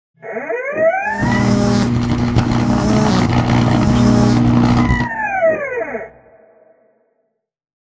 shock-short.wav